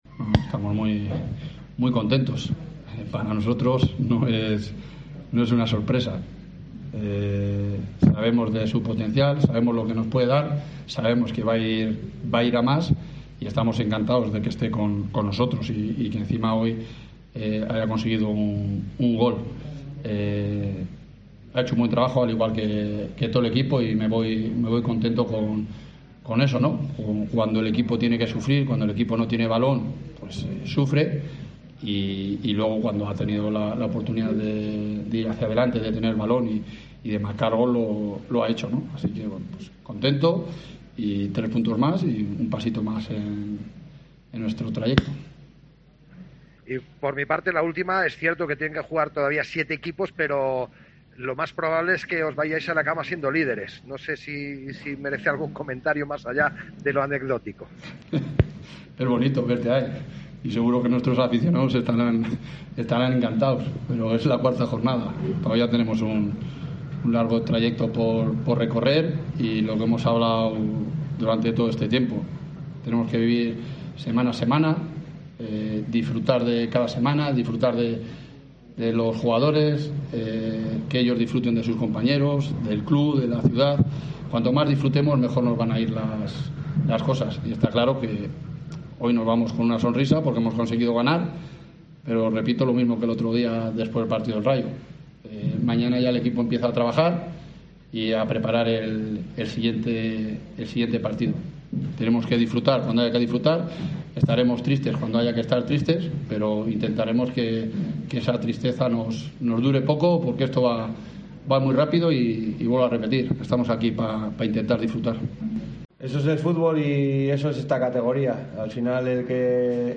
Escucha aquí el postpartido con declaraciones